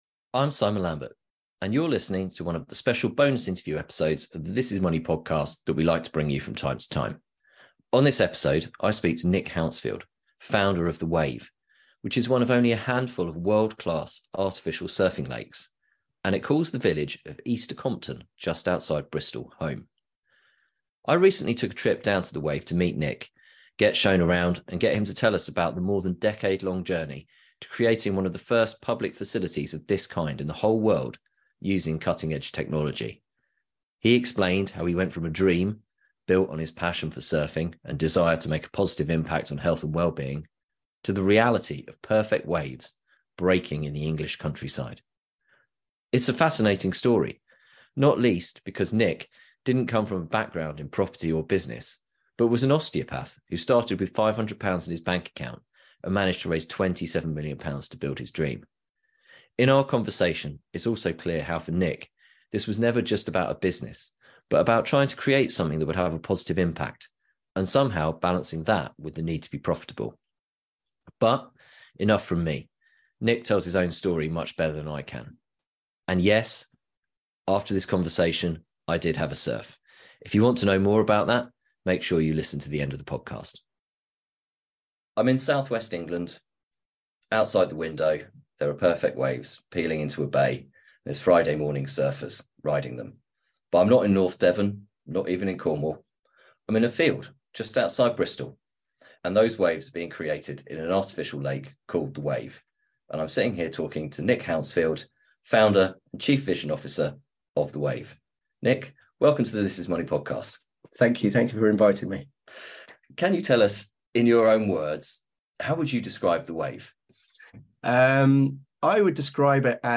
But this slice of the English countryside is home to The Wave, an artificial surfing lake that is one of just a handful in the world to use cutting edge technology and was the first of its kind.